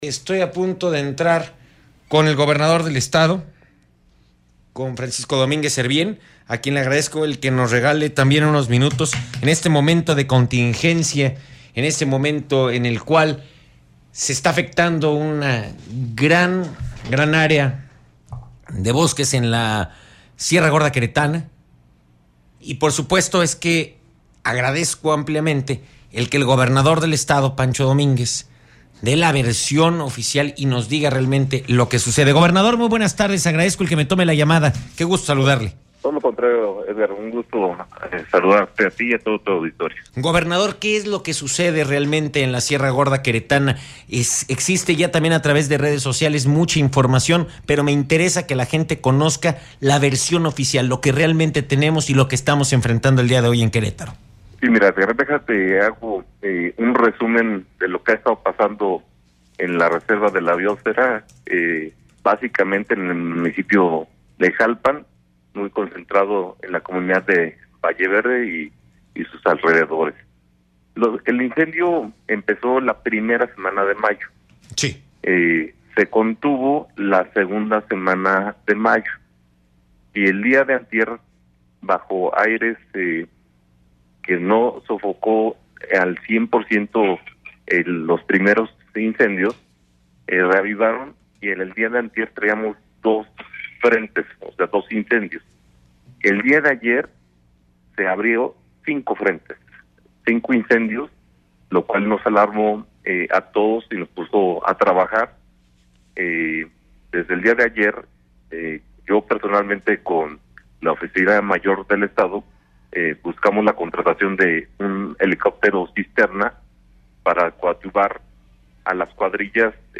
Entrevista con el Gobernador Francisco Domínguez Servién sobre el incendio en la Sierra - RR Noticias